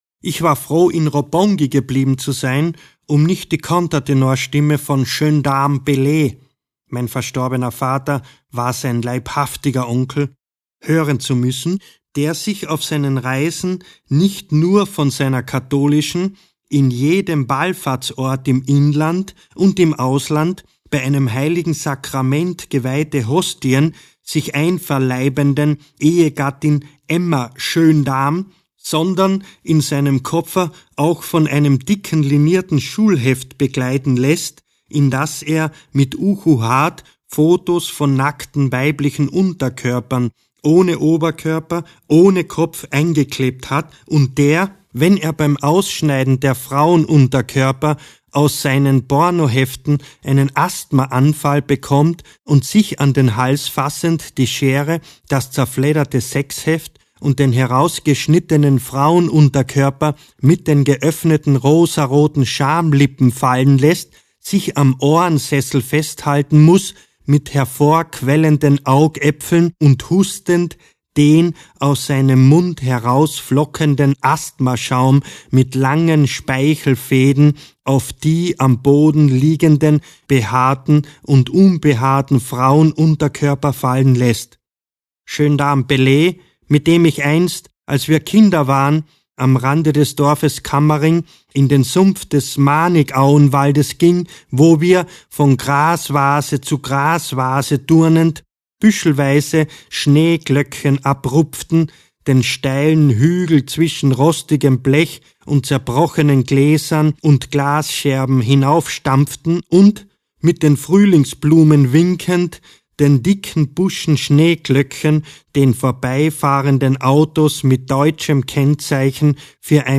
Requiem für einen Vater. Ungekürzte Lesung
Josef Winkler (Sprecher)